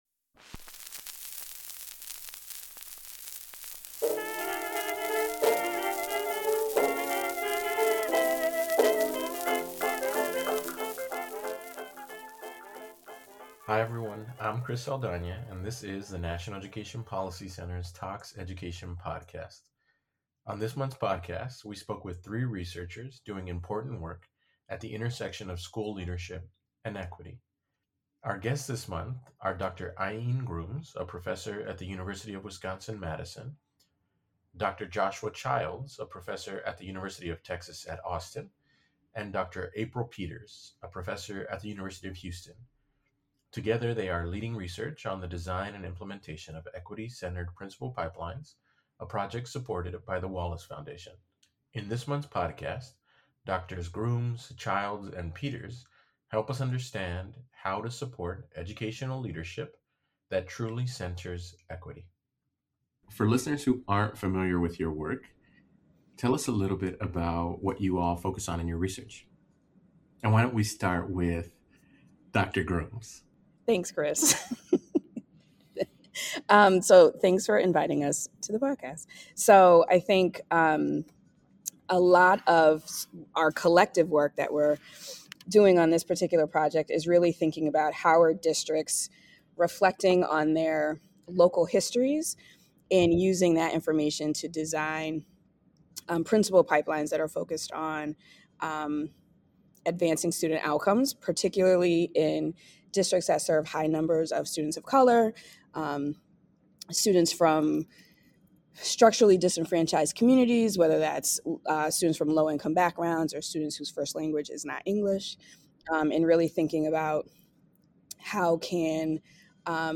Education Interview